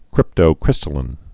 (krĭptō-krĭstə-lĭn, -līn)